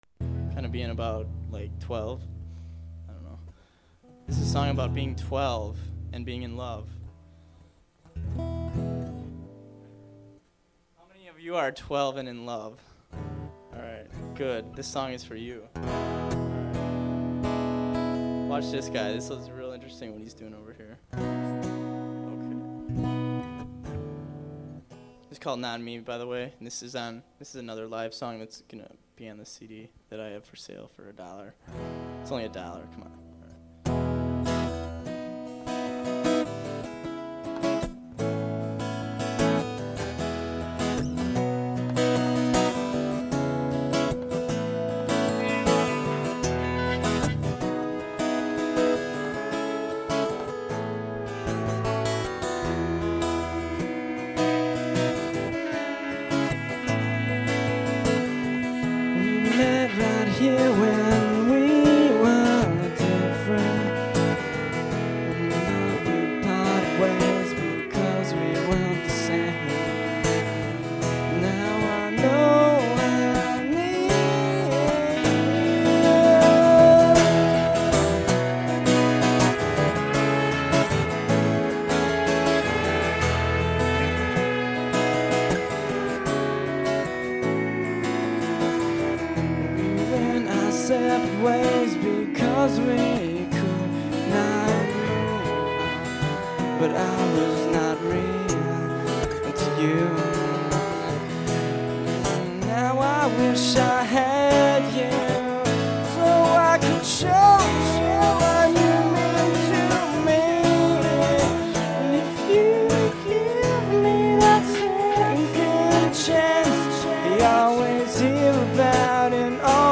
(solo)